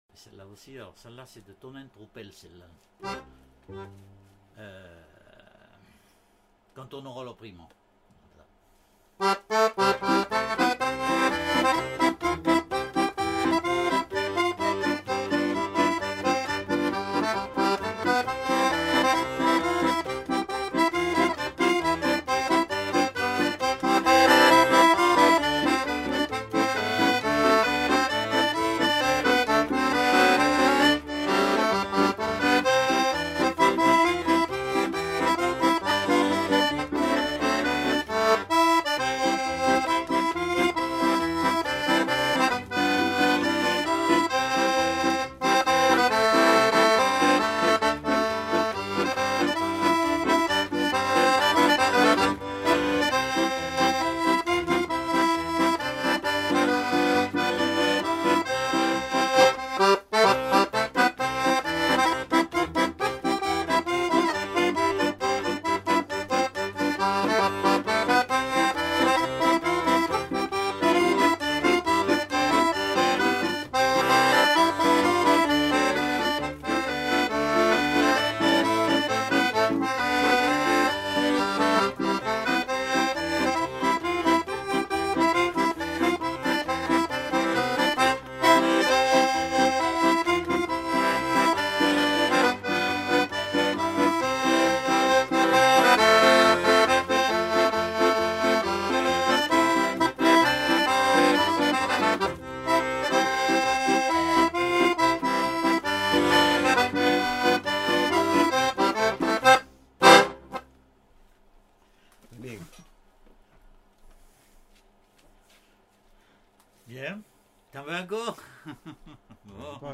Aire culturelle : Quercy
Genre : morceau instrumental
Instrument de musique : accordéon chromatique
Danse : marche (danse)
Notes consultables : L'informateur joue quelques notes de La planière puis s'interrompt.